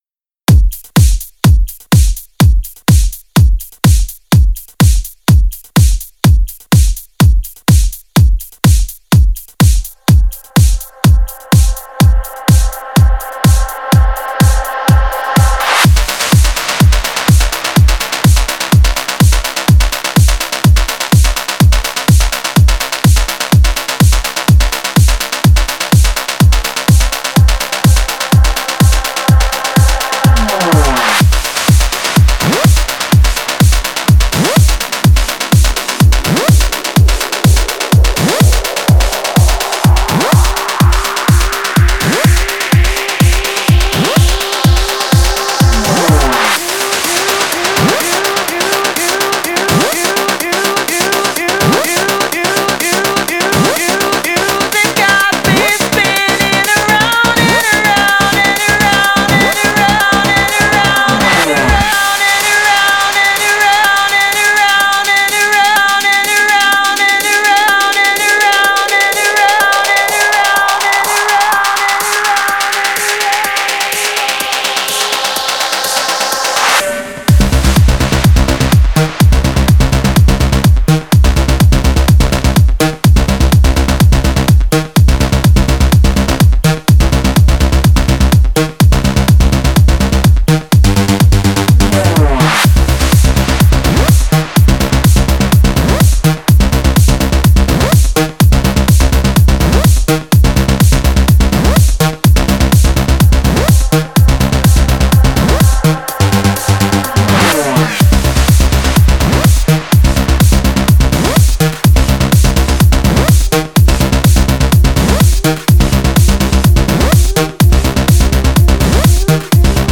Жанр:Dance
я думаю немного перегружен звучанием